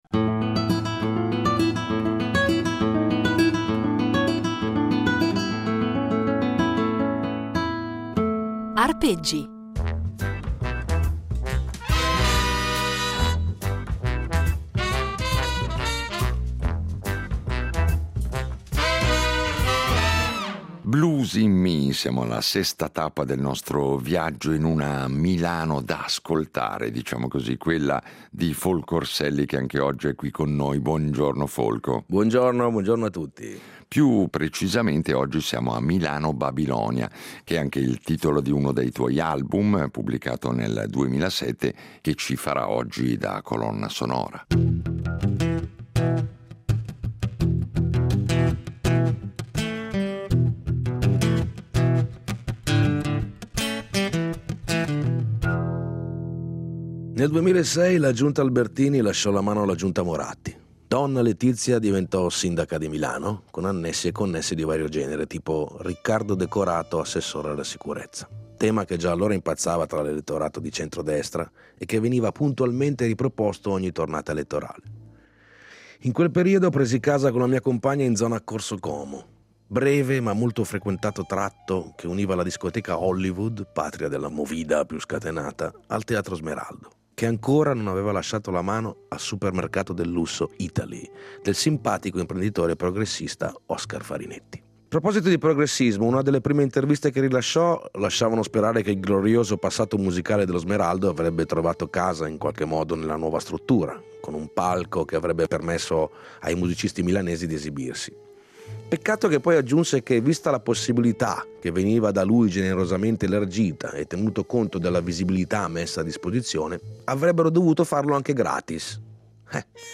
Un itinerario impreziosito, in ogni puntata, da un brano eseguito solo per noi, ai nostri microfoni.